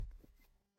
A thick hardcover book dropping flat onto a wooden surface with a solid thud
book-drop.mp3